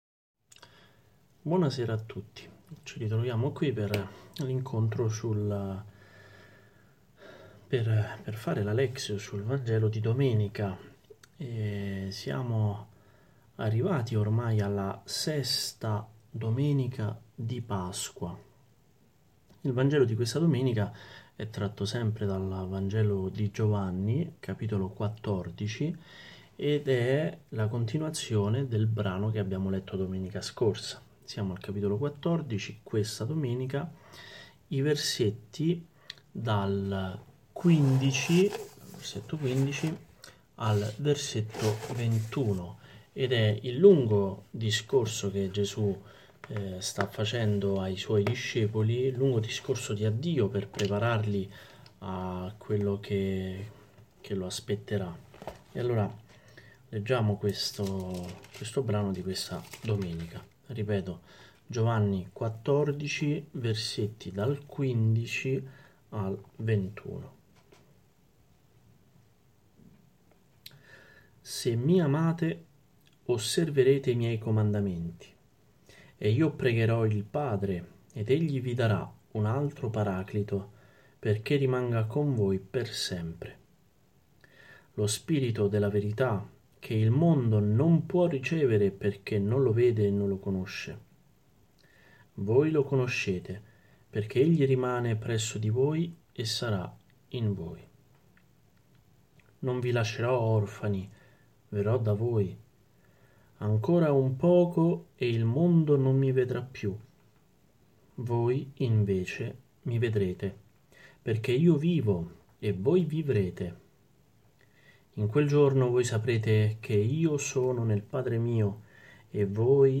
Tipo: Audio Catechesi